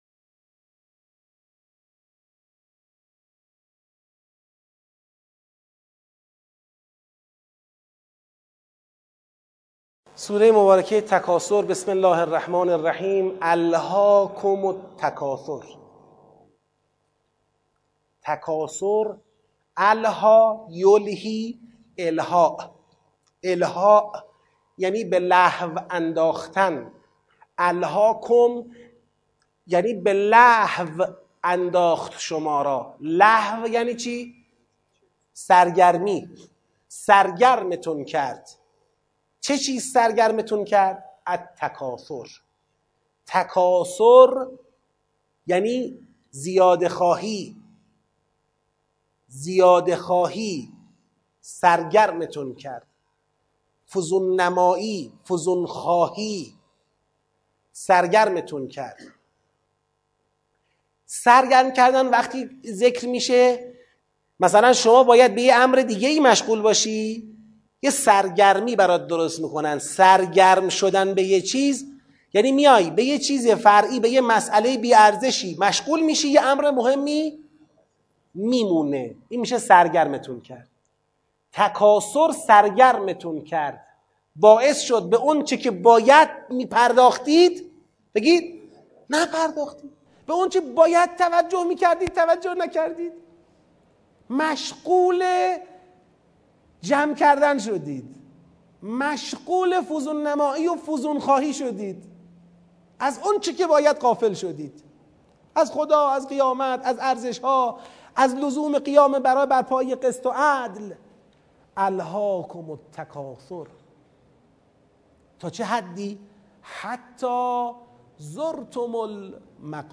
آموزش تدبر در سوره تکاثر